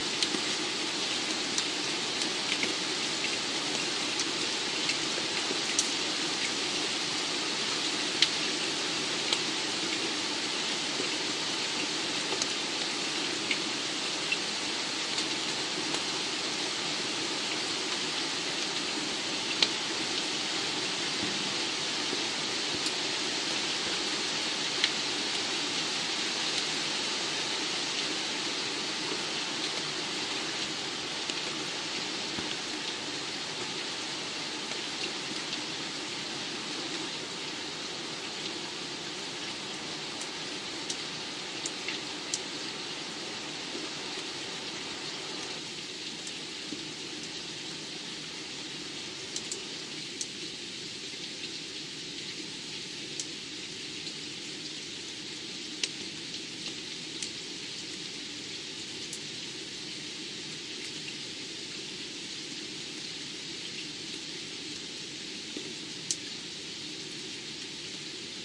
2017年7月23日的雷雨之夜 " 雨夜2017年7月23日
描述：在2017年7月23日（2:30 AM）雷暴期间，雨倒在我家门前的街道上 录制于2017年7月23日至7月24日夜间，我的JVC GZR415BE摄像机内置麦克风，位于法国GrandEst的Alutace，HautRhin村庄，因为多细胞雷暴袭击了该镇。